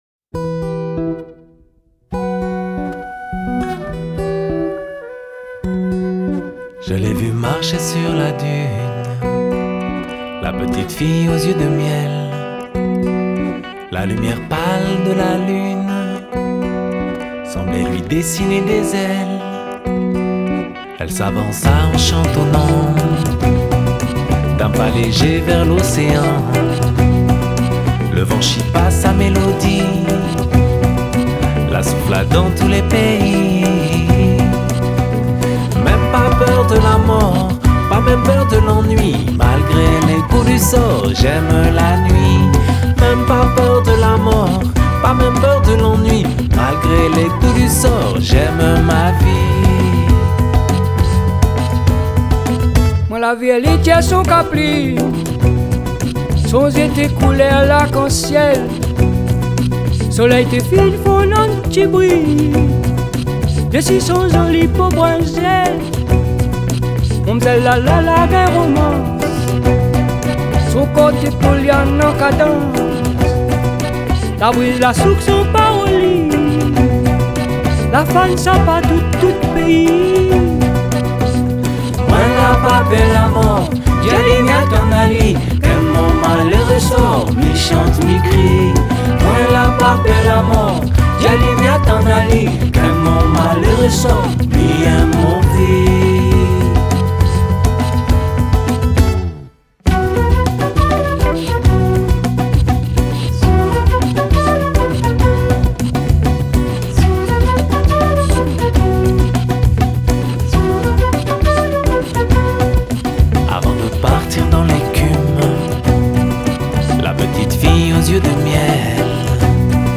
Genre: World, Folk